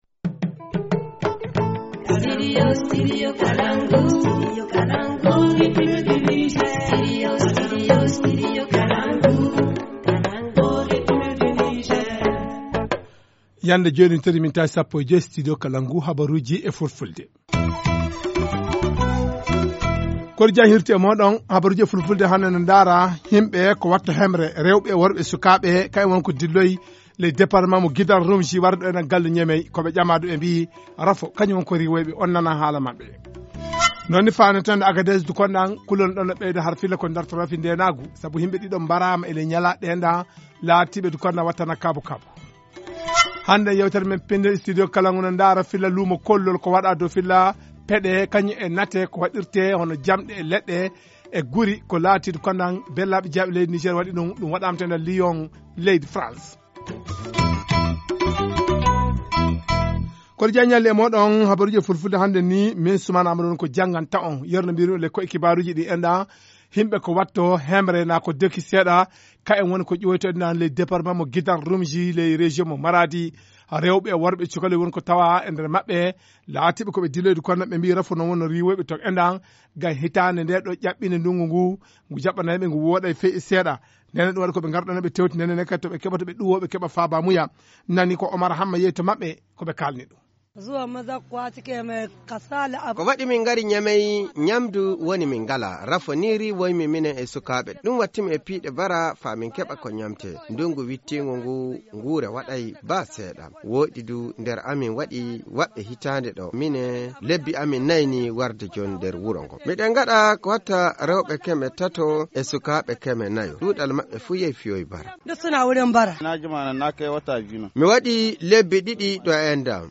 Journal du 1er Février 2018 - Studio Kalangou - Au rythme du Niger